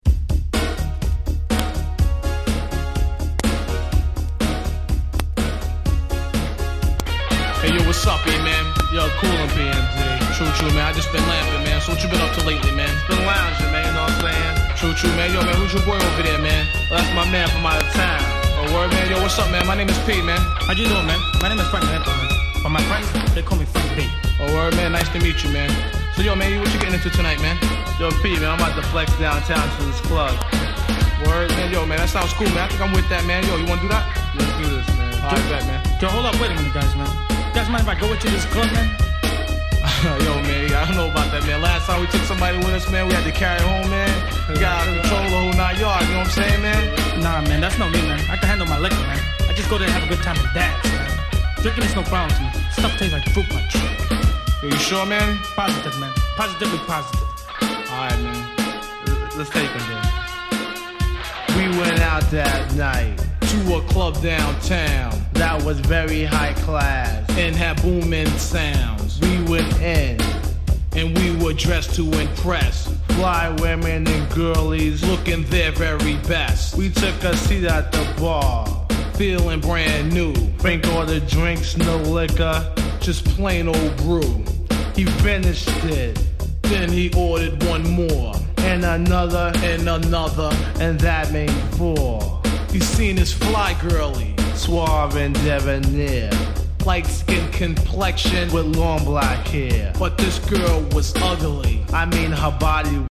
※試聴ファイルは別の盤から録音してあります。
89' Smash Hit Hip Hop !!